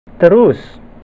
speaker.gif (931 bytes) Click on the word to hear it pronounced.